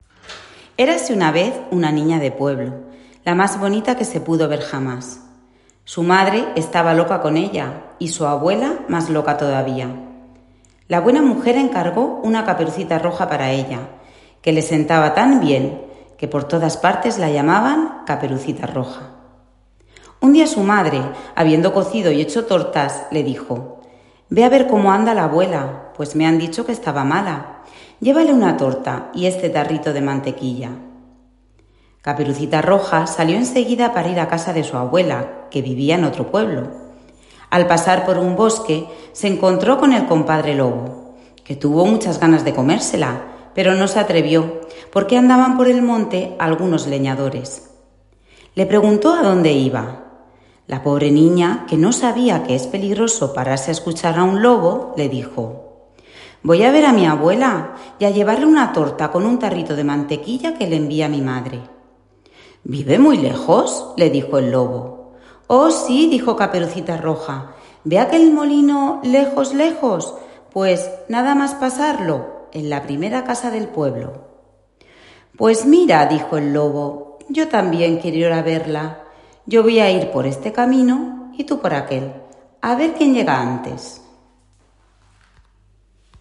Fragmento de cuento narrado